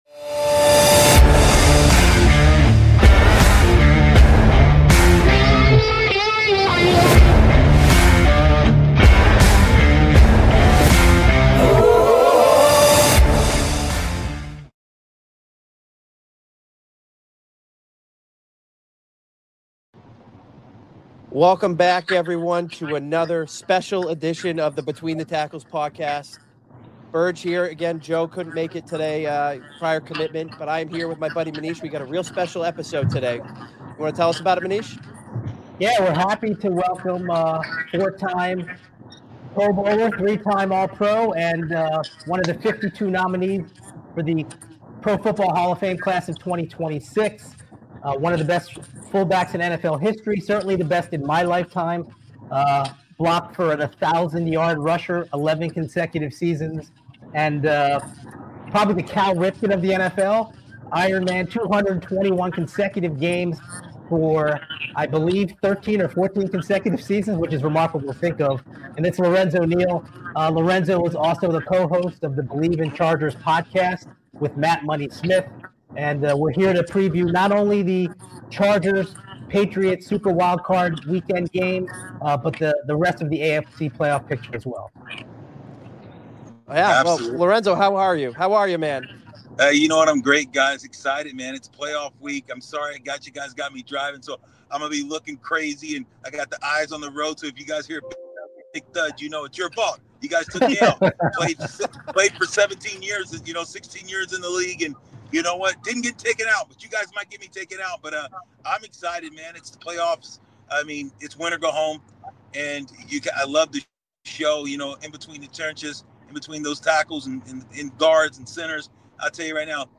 We have you covered with fantasy football, hot takes, greatest of all time lists and more! If you enjoy a couple of old buddies just sitting back, arguing and sometimes calling each other idiots, smash that play button and let the good times roll.